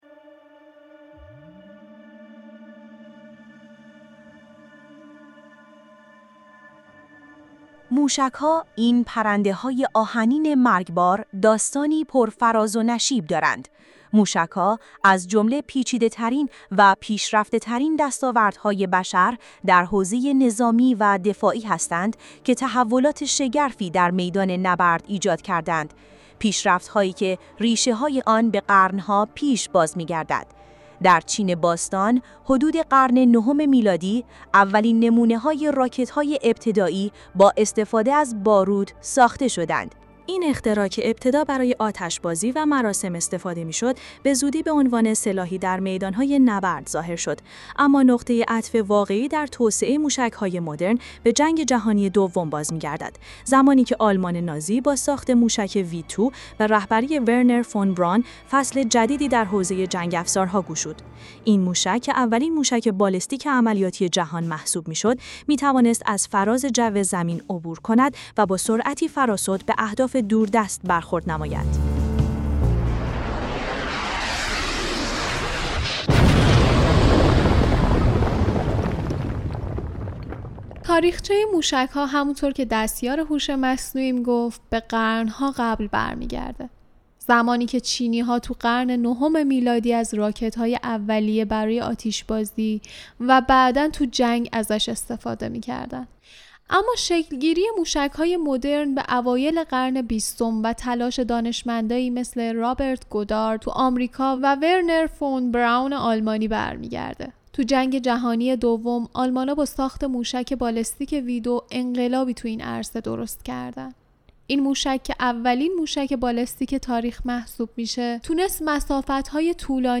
اپیزودهای ما در این شروع جدید، برخلاف فصل قبلی که با دیالوگی بین انسان و هوش مصنوعی (جمینی) شروع میشد، این بار با مونولوگی از یک هوش مصنوعی متفاوت (دیپ سیک ) شروع می‌شود.